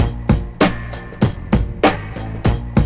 BEAT 4 97 03.wav